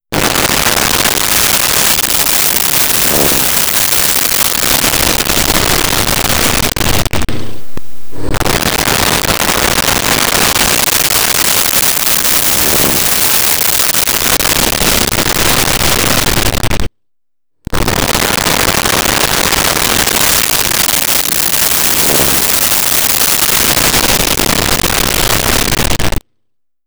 Prop Plane Bys
Prop Plane Bys.wav